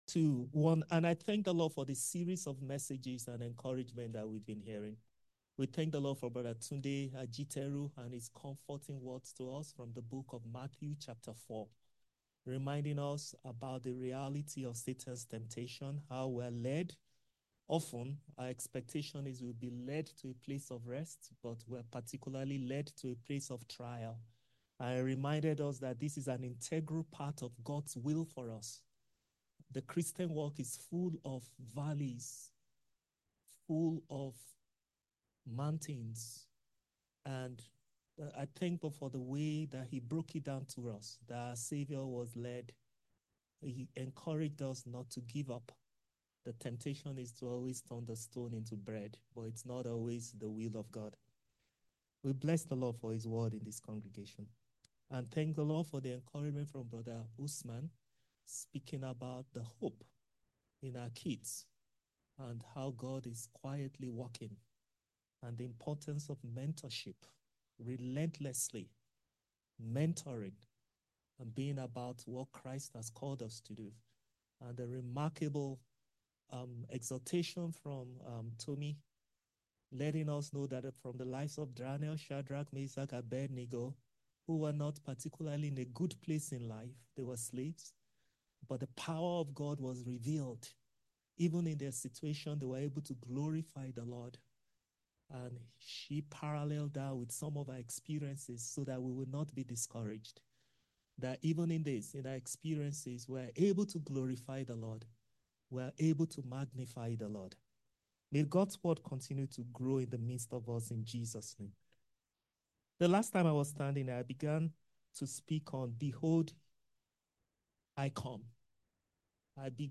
A message from the series "Sermons."